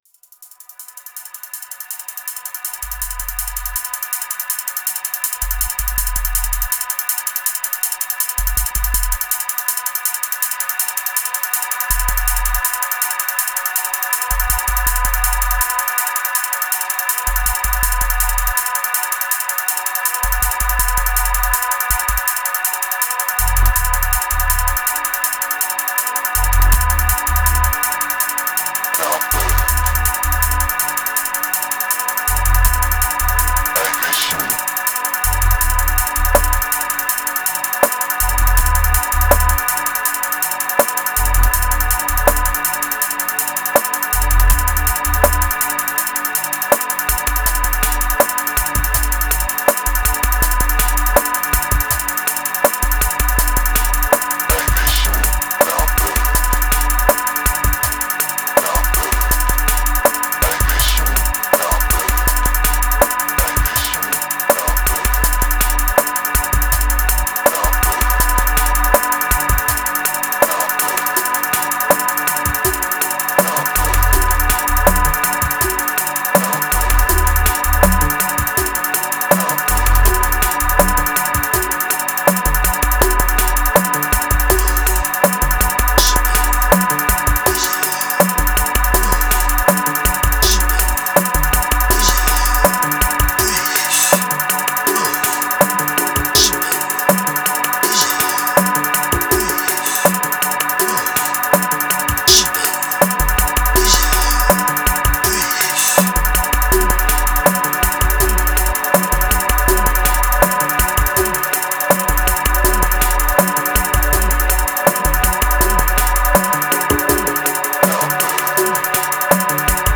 892📈 - 87%🤔 - 81BPM🔊 - 2020-07-14📅 - 468🌟
Dark Trip Sampler Riddim Hope Relief Voices Talkwover Epic